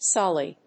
/ˈsɑli(米国英語), ˈsɑ:li:(英国英語)/